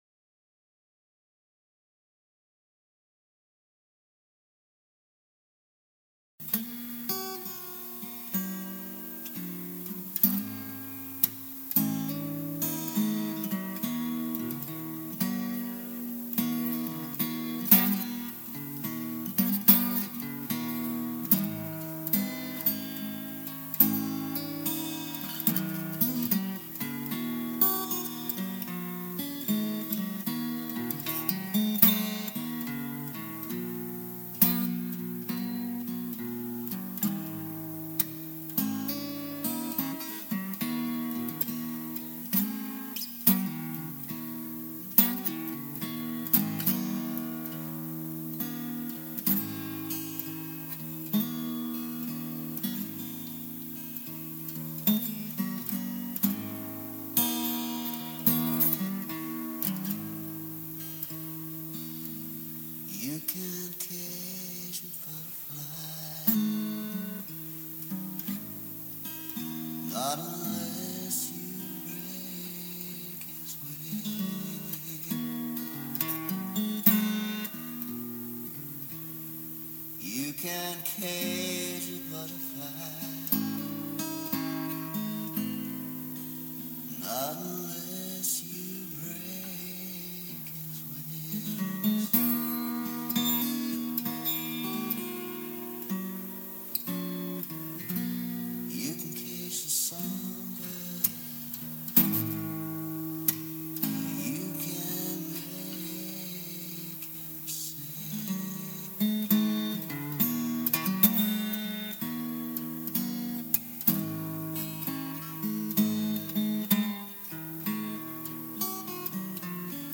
Essentially a 12- bar but with more minor chording than you might expect.
Here’s a much older, slower acoustic version.